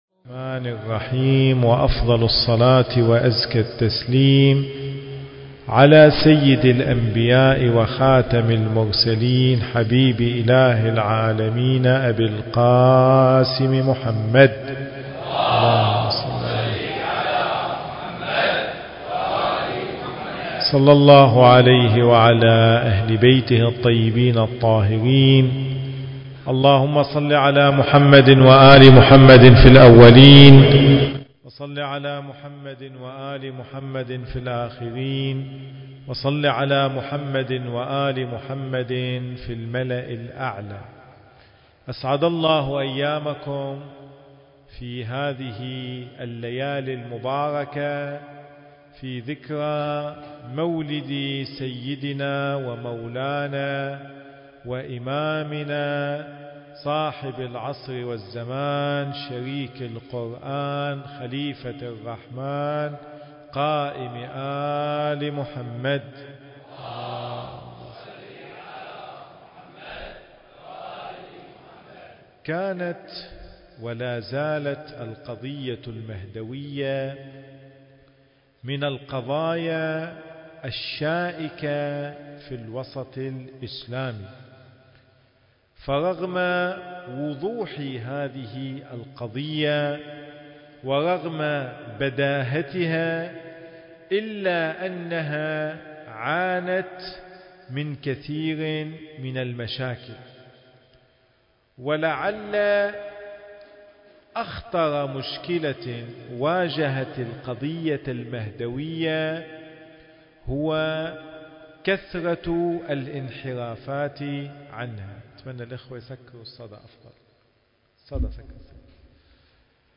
المكان: مسجد وحسينية أم البنين (عليها السلام) - بغداد التاريخ: 1443 للهجرة